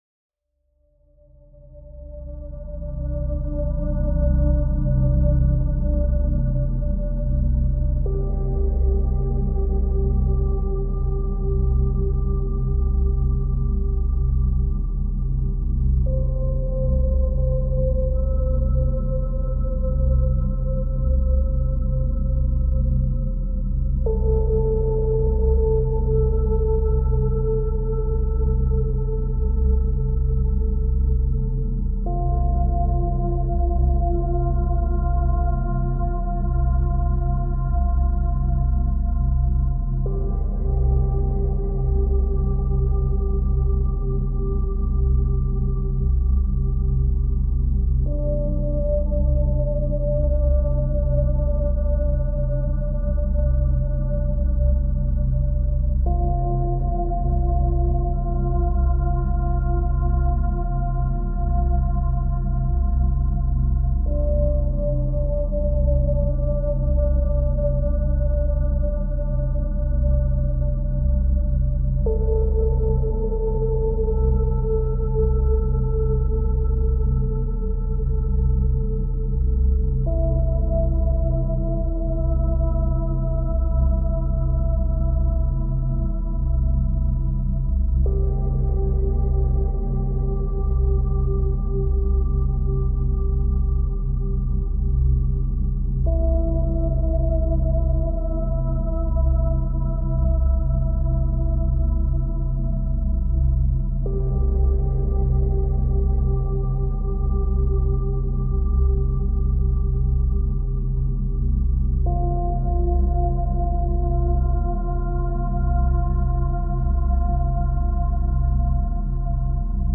SOLSTICE Energy - 25 hz- Beta Binaural Beats for Deep Stillness ~ Binaural Beats Meditation for Sleep Podcast
Mindfulness and sound healing — woven into every frequency.